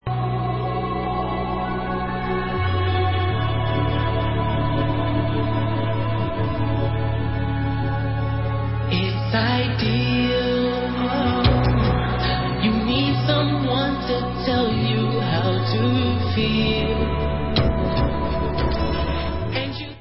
hrající fantastický R&B pop.